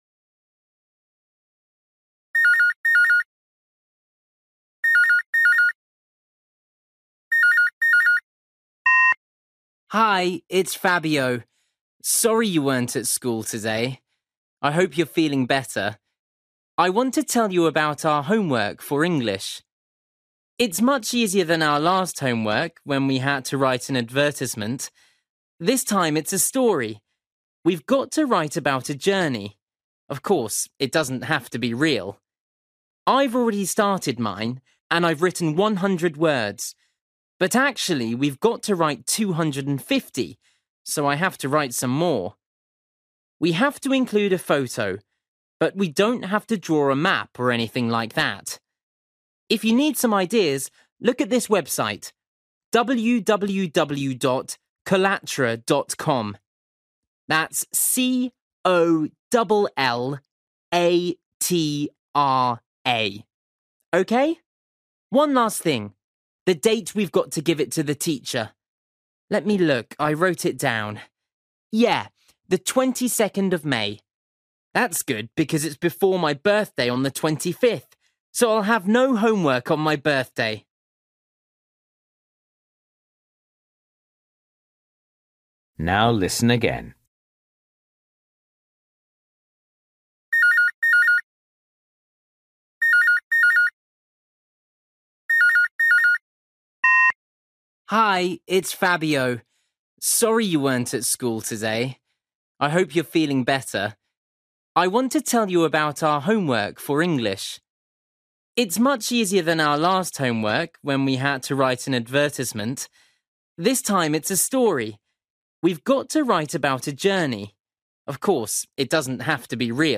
You will hear a boy leaving a message for a friend about some homework.